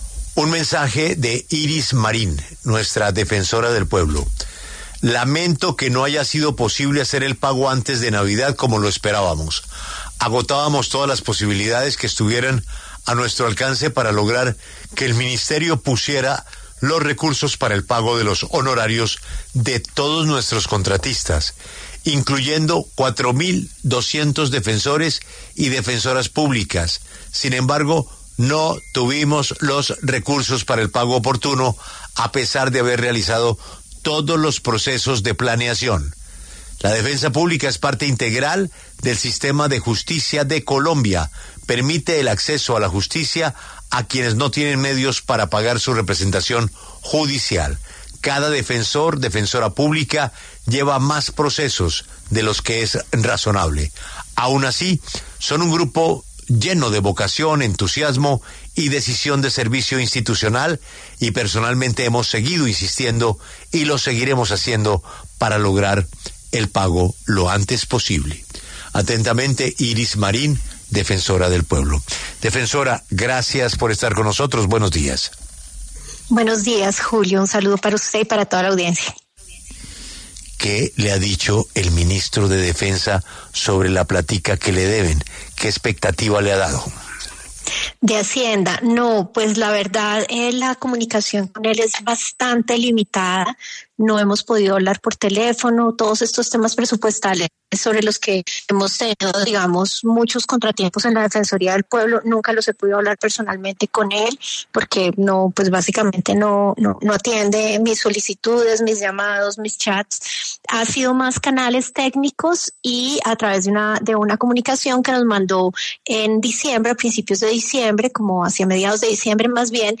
La defensora del Pueblo, Iris Marín, pasó por los micrófonos de La W, con Julio Sánchez Cristo, para hablar acerca de la demora en el pago de diciembre para sus contratistas por parte del Ministerio de Hacienda.